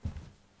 krok_01.wav